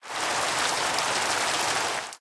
Minecraft Version Minecraft Version 1.21.5 Latest Release | Latest Snapshot 1.21.5 / assets / minecraft / sounds / ambient / weather / rain8.ogg Compare With Compare With Latest Release | Latest Snapshot
rain8.ogg